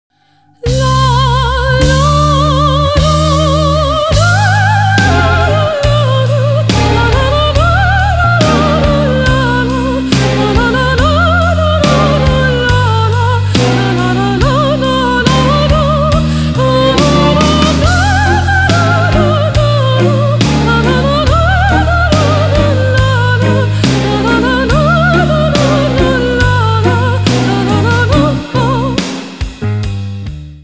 • Качество: 192, Stereo
женский вокал
Cover
Metal